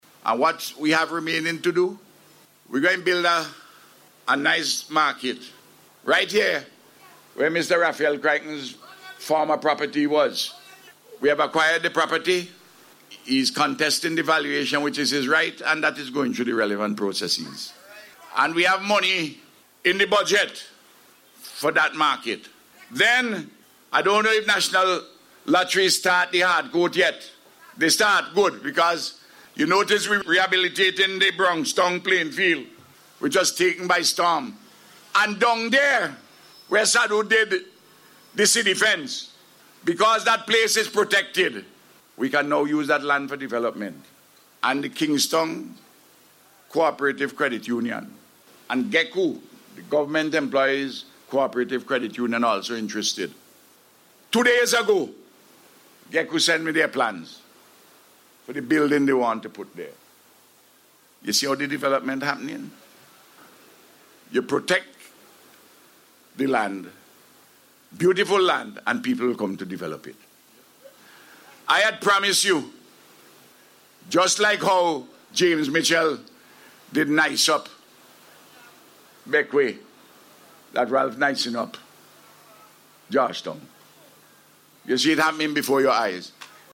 The Prime Minister outlined some of the projects to be undertaken, while delivering the feature address at the opening of the Coastal Defense Projects in San Souci and Georgetown.